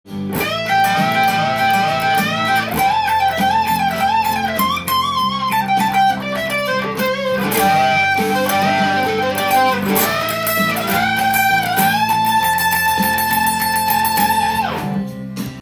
試しにハイポジションフレーズをカラオケにあわせて弾いてみた